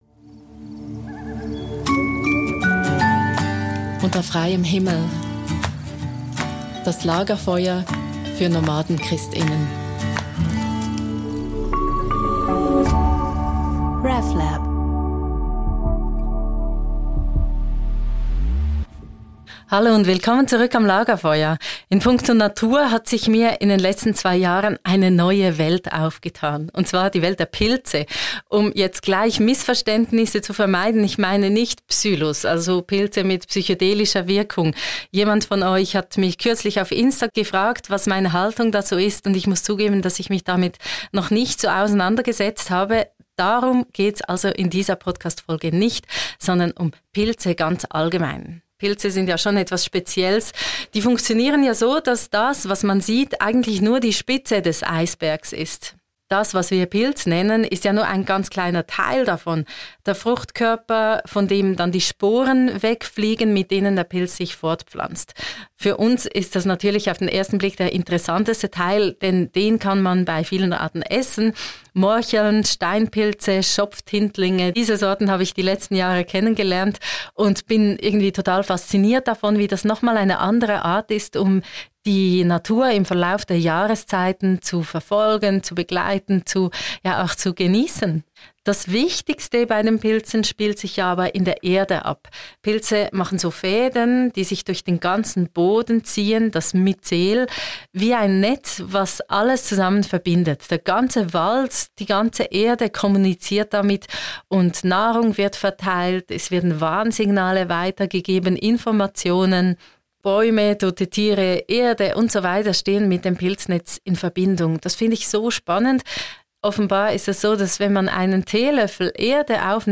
Nach dem Outro (ca. ab Minute 7:30) also die Predigt.